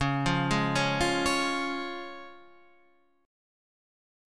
C# chord